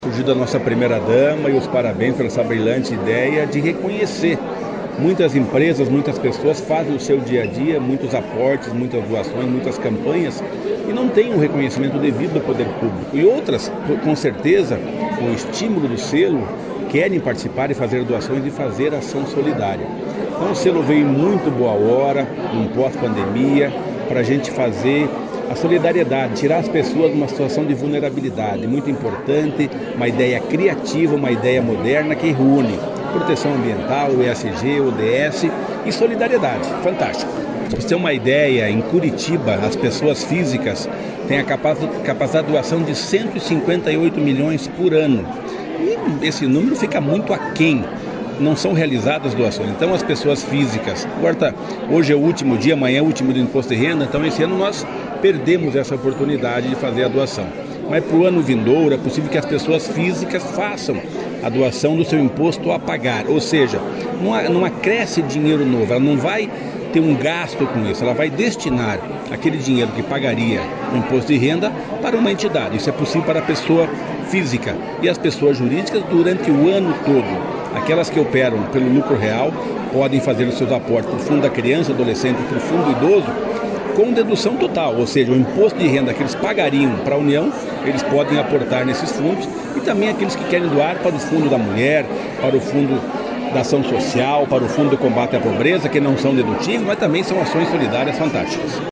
Sonora do secretário Estadual do Desenvolvimento Social e Família, Rogério Carboni, sobre o lançamento do prêmio Selo Solidário